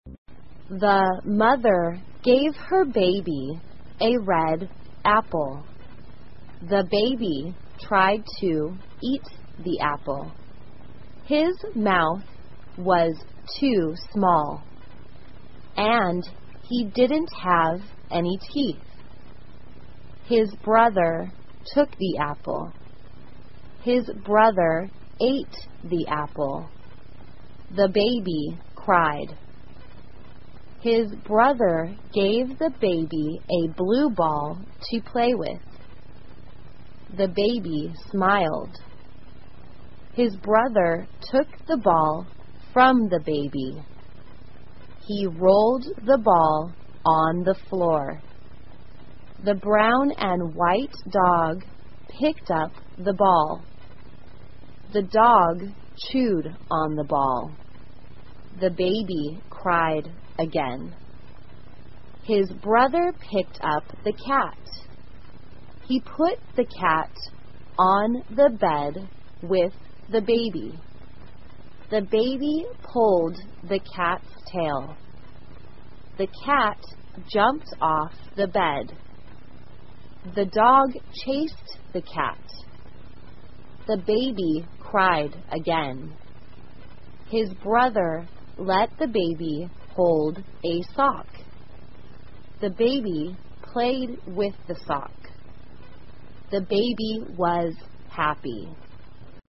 慢速英语短文听力 宝宝和袜子 听力文件下载—在线英语听力室